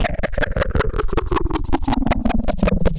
Index of /musicradar/rhythmic-inspiration-samples/80bpm
RI_ArpegiFex_80-05.wav